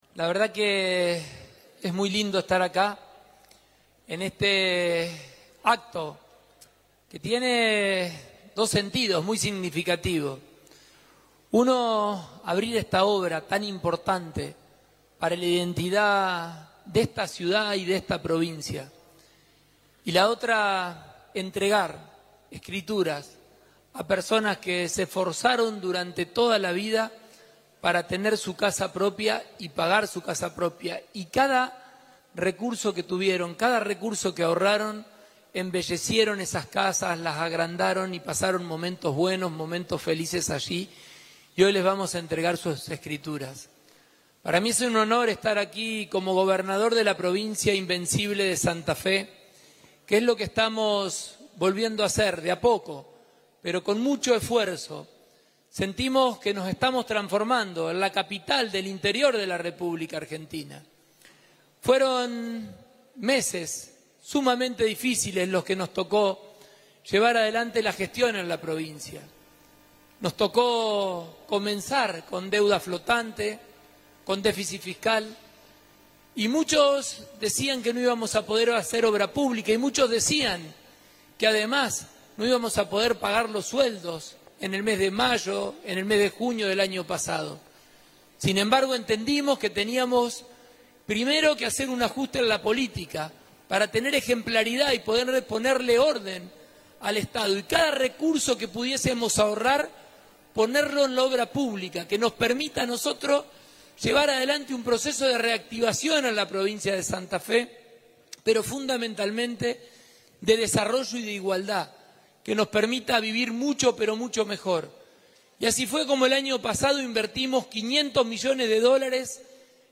Lo dijo el gobernador al encabezar en Rosario el acto de apertura de ofertas para la intervención integral del Parque España.